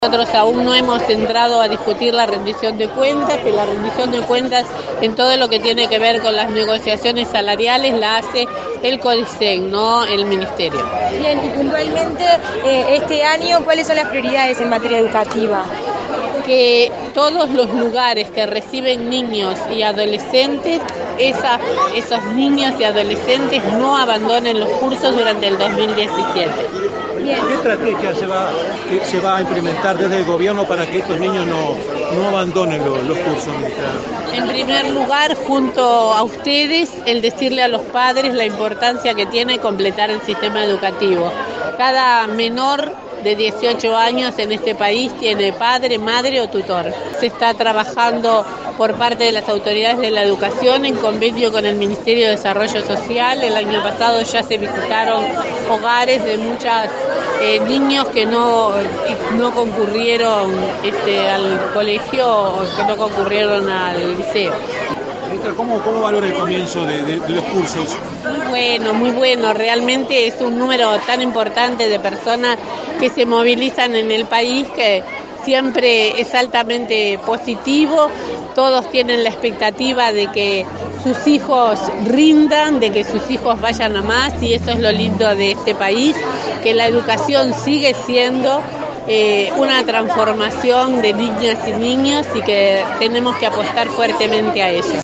“El cambio de ADN de la educación está en marcha y tiene que ver con la transformación profunda para que dentro del sistema estén todos los niños y niñas de Uruguay”, afirmó a la prensa la ministra de Educación, María Julia Muñoz, tras recorrer este lunes distintos centros de estudios del área metropolitana en el primer día de clases. Insistió en la necesidad de evitar las inasistencias a clase de los alumnos.